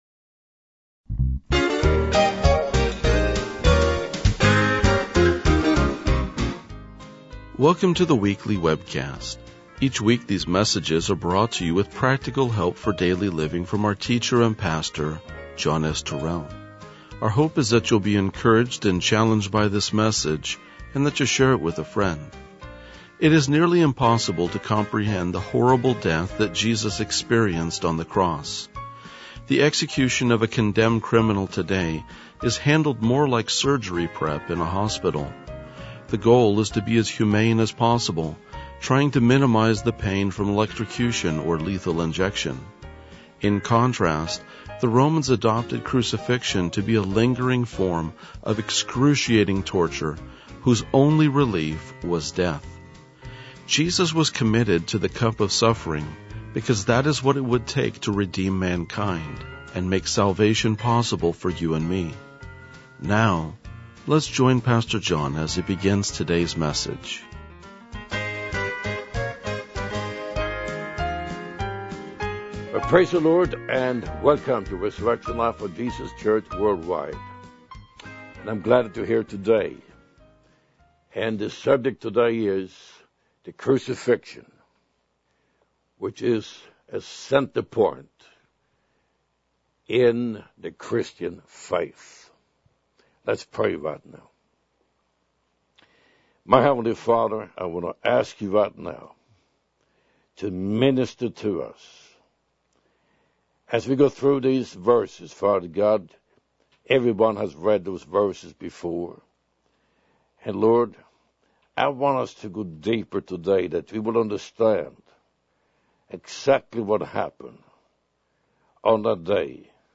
RLJ-1983-Sermon.mp3